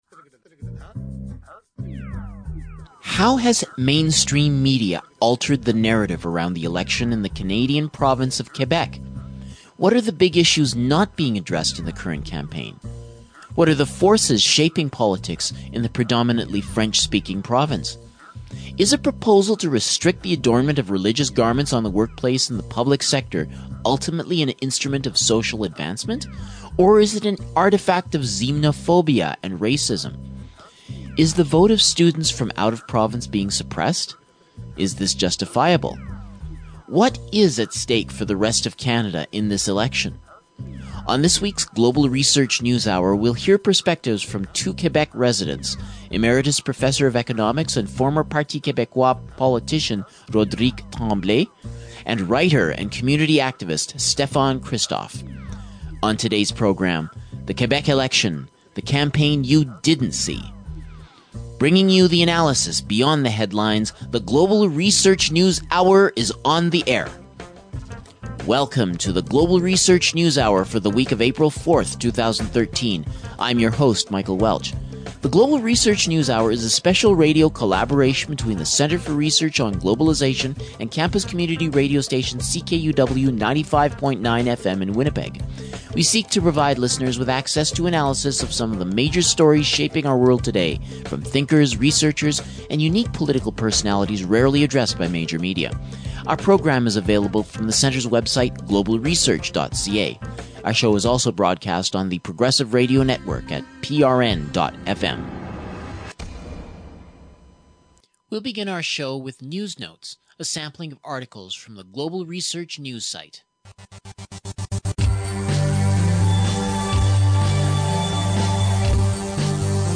Election analysis